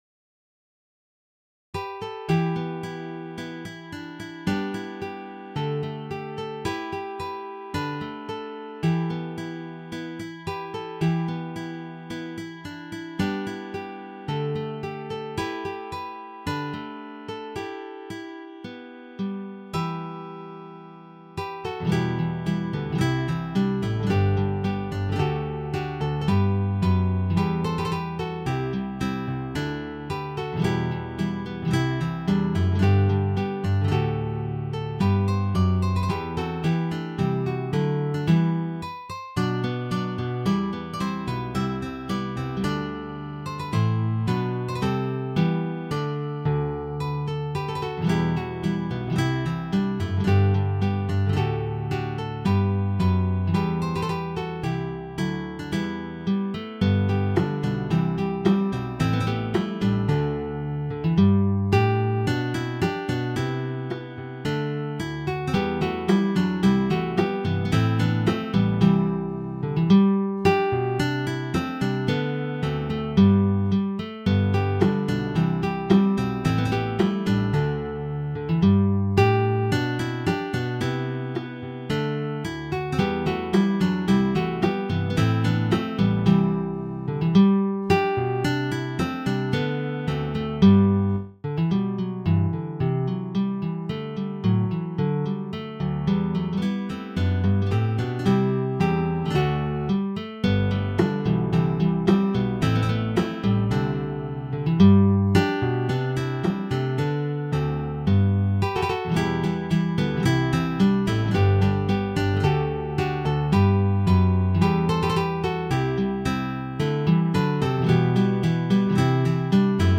Expanded version is standard guitars and contrabass.